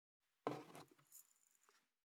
2025年2月19日 / 最終更新日時 : 2025年2月19日 cross 効果音
399,ゴト,カタ,ザッ,ヌルッ,キュッ,ギギッ,シャッ,スリッ,ズルッ,
効果音厨房/台所/レストラン/kitchen